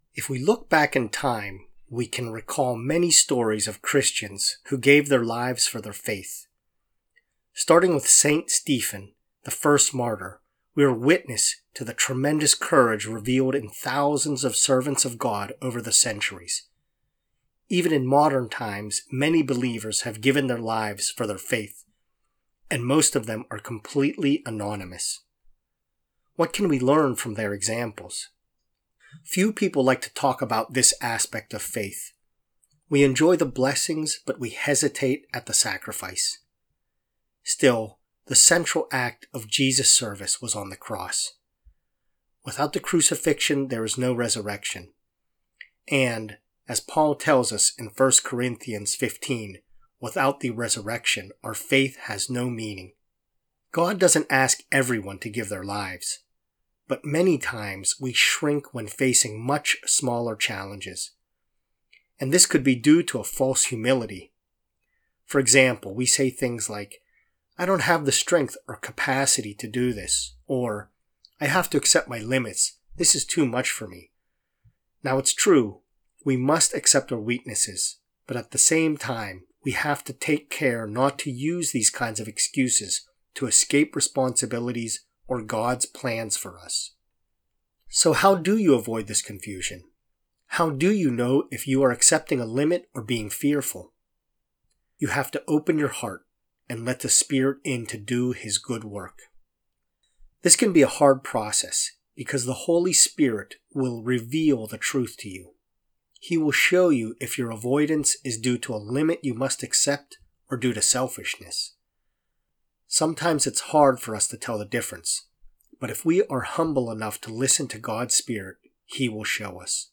Prayer-to-trust-more-in-Gods-Spirit.mp3